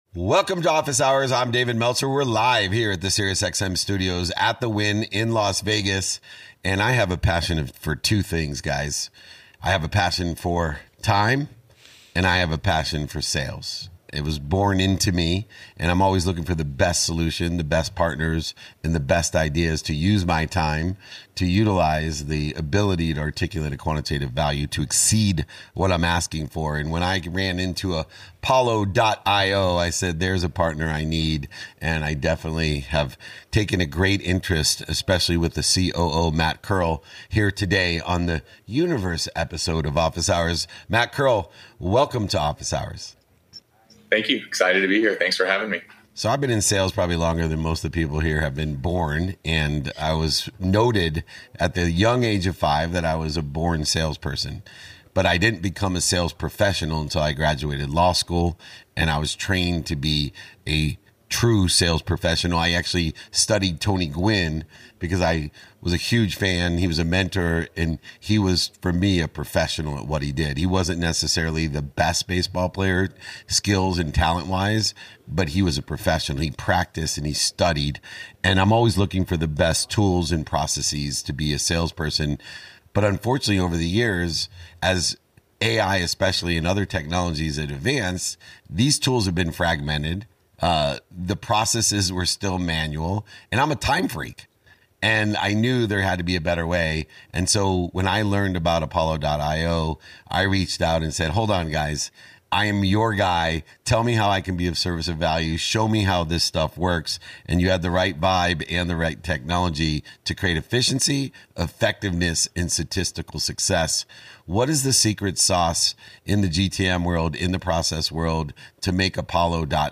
From cutting wasted time to multiplying results, this conversation unpacks how the future of sales belongs to those who learn to work smarter with AI.